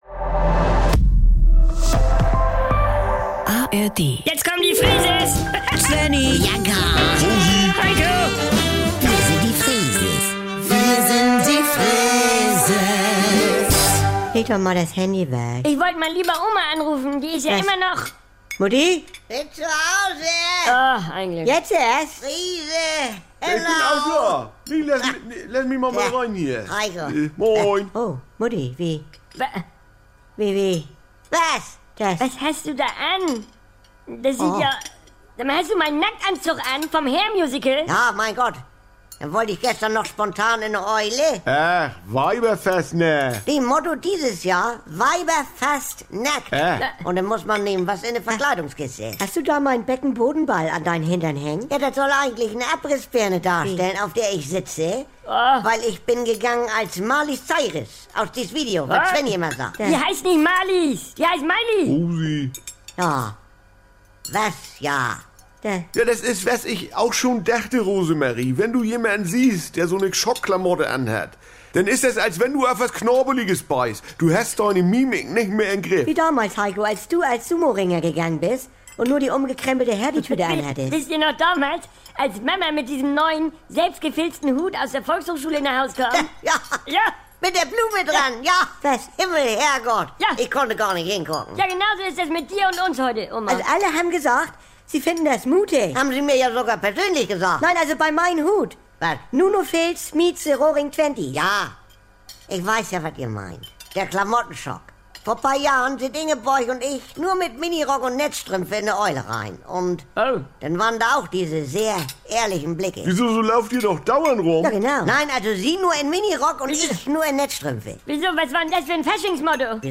Hier gibt's täglich die aktuelle Freeses-Folge, direkt aus dem Mehrgenerationen-Haushalt der Familie Freese mit der lasziv-zupackenden Oma Rosi, Helikopter-Mama Bianca, dem inselbegabten Svenni sowie Untermieter und Labertasche Heiko. Alltagsbewältigung rustikal-norddeutsch...